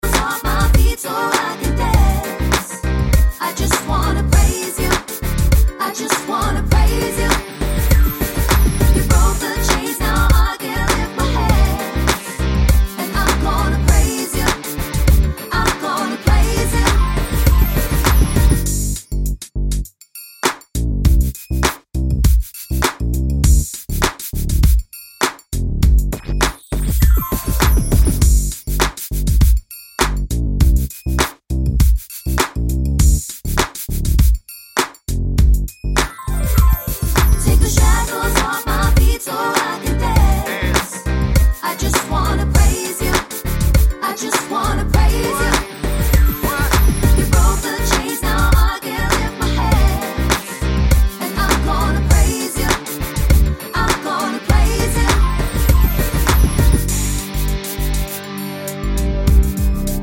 Pop (2000s) 3:18 Buy £1.50